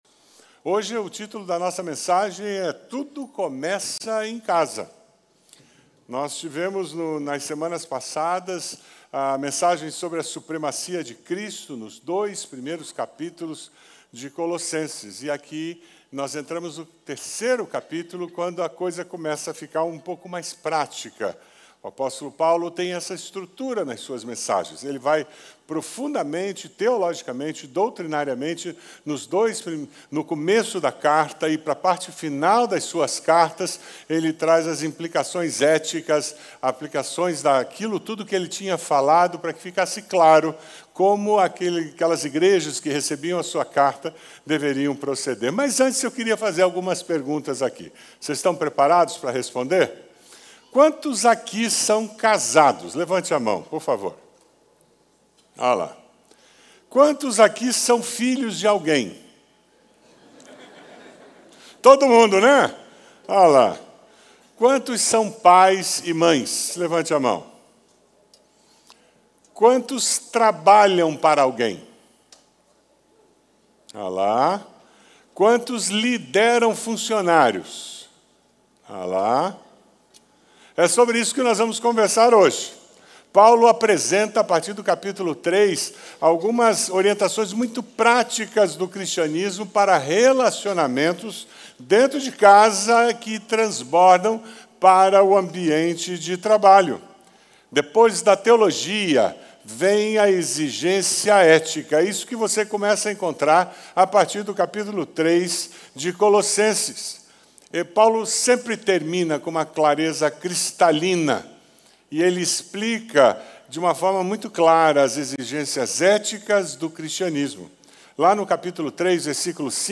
na Igreja Batista do Bacacheri.